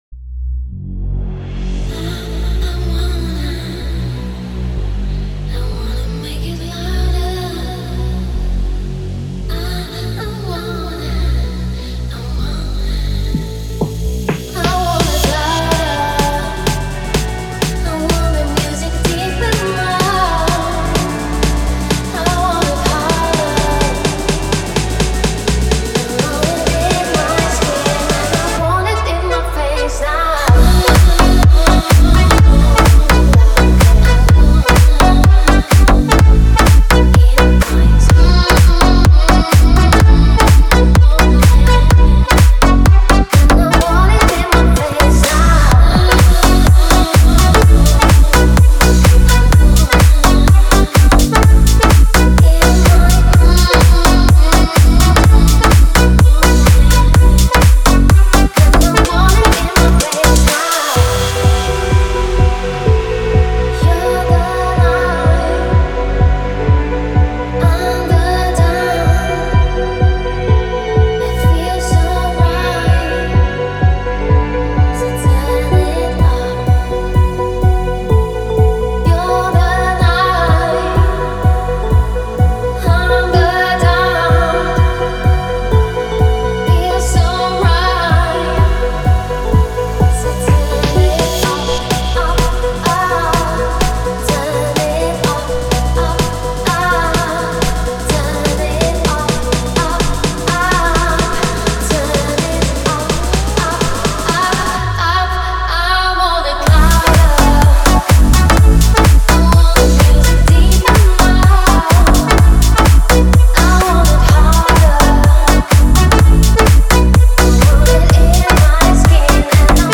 это энергичная электронная композиция в жанре EDM